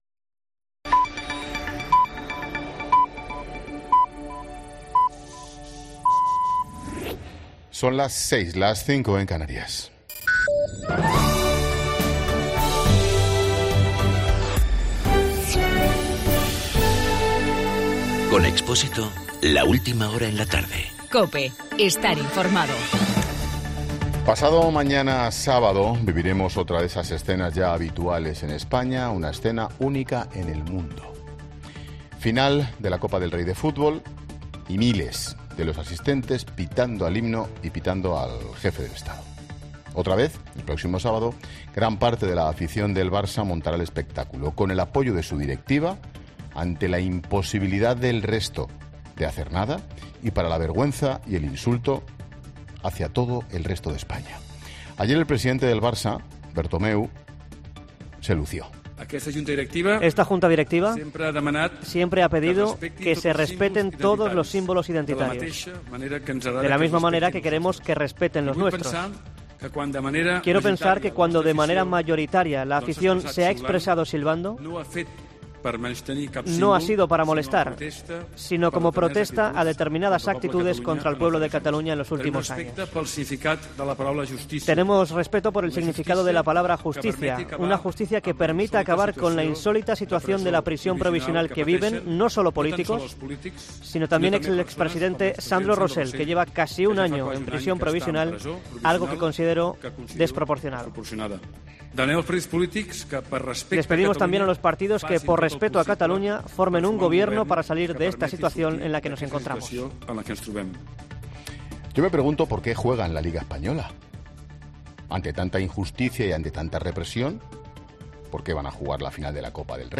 Monólogo de Expósito
Comentario de Ángel Expósito a las 18 horas sobre la crisis catalana.